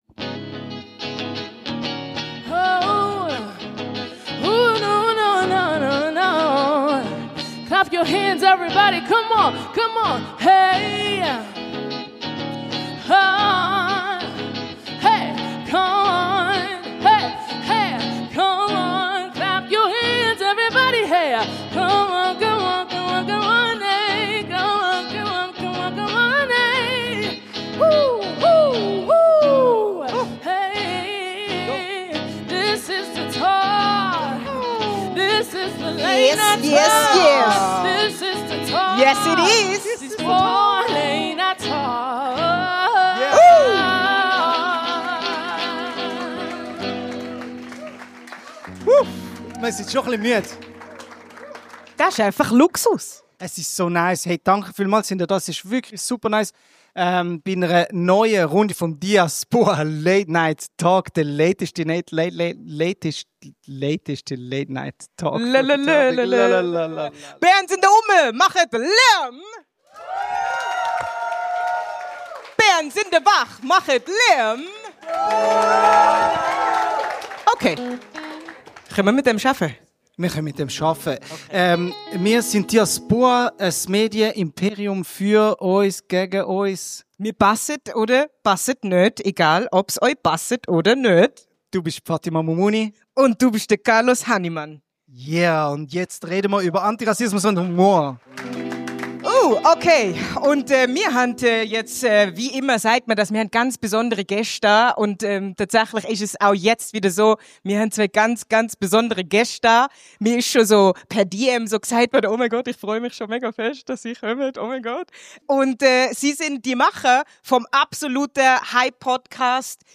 Beschreibung vor 9 Monaten Das ist die dritte von drei Folgen der DIASBOAH Late Night Talks, aufgenommen am 21. März 2025 in der Kornhausbibliothek Bern.